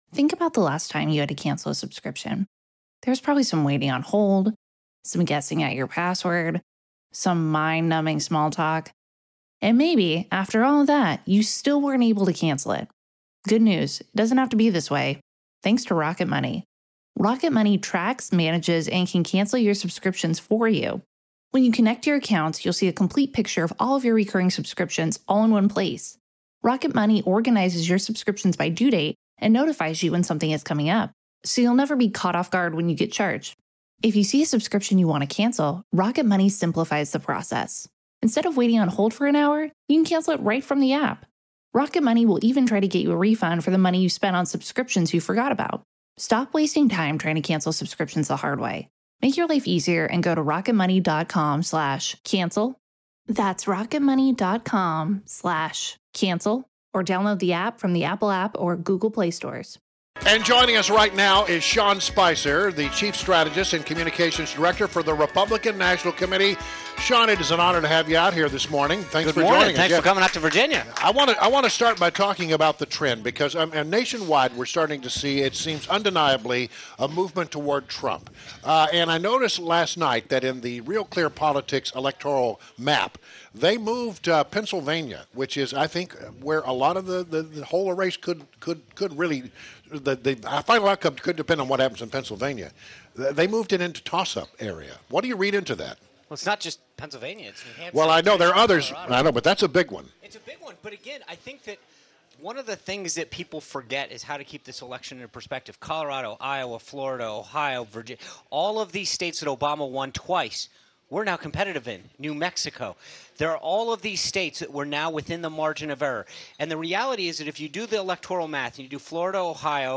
WMAL Interview - SEAN SPICER - 11.04.16